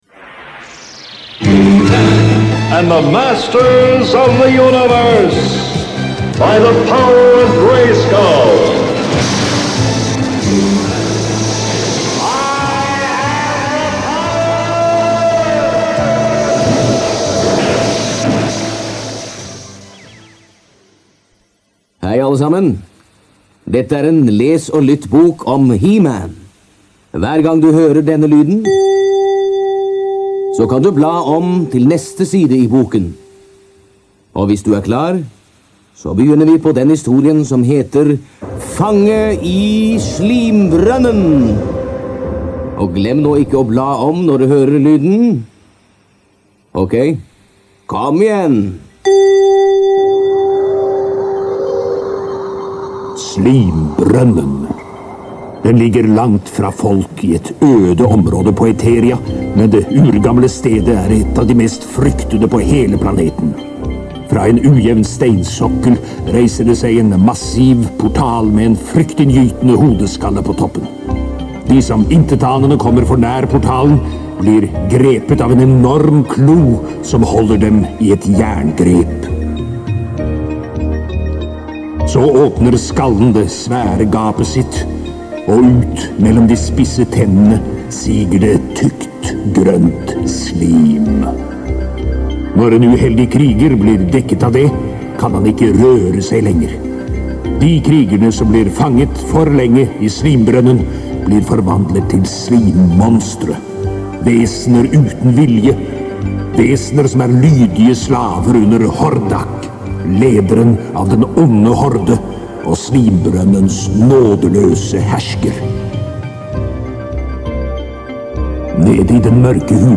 The audio recording below was found on YouTube, extracted from video, cleaned up and amplified and converted to MP3 below.
He-Man Audio Book Tape Norwegian
he-man_audio-tape_norwegian.mp3